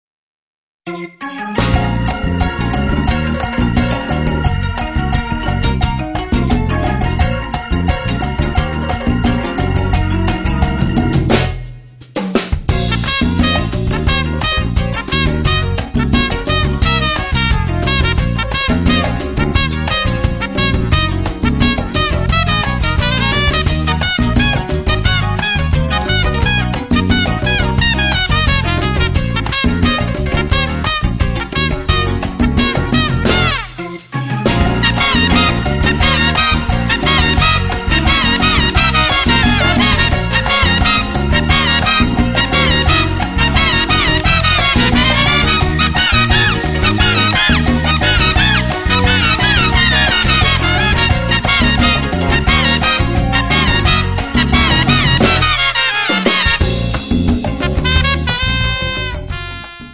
tromba, flicorno